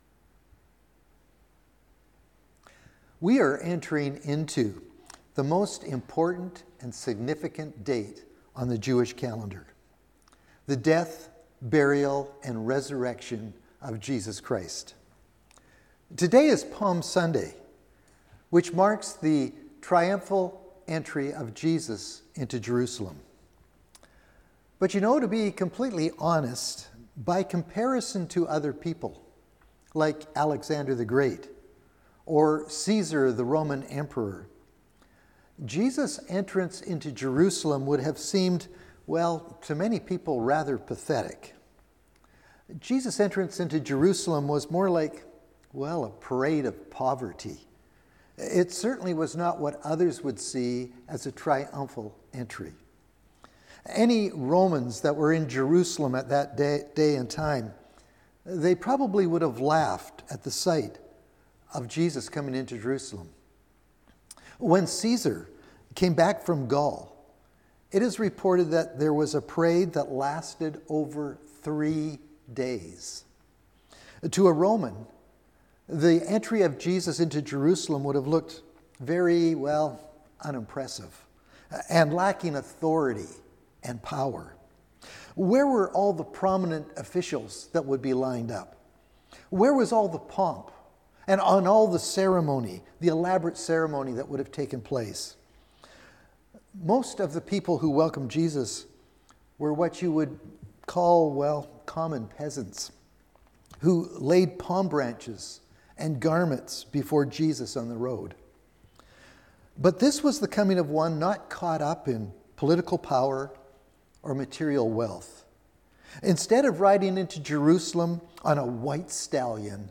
Sermons | Cumberland Community Church